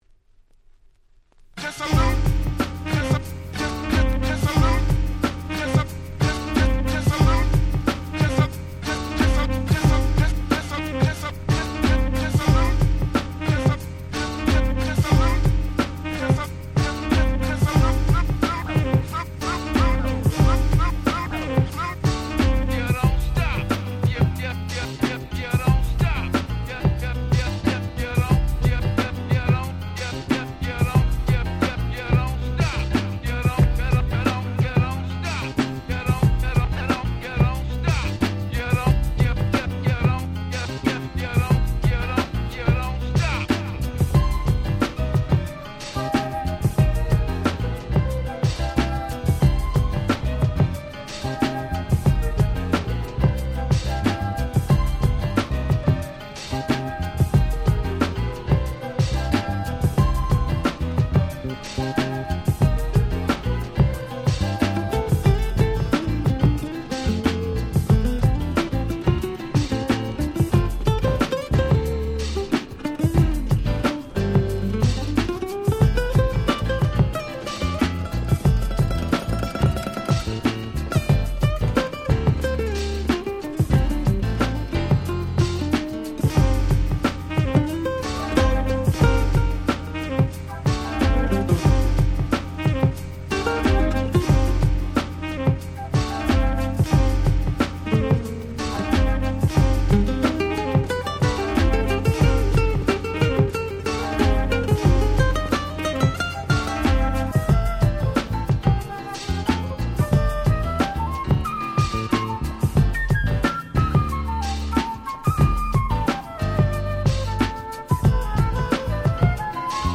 92' UK Soul Super Classics !!
原曲の良さを決して損なわずによりフロア向けに仕上げた好Remixです。
アシッドジャズ UK Soul